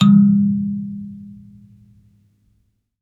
kalimba_bass-G#2-mf.wav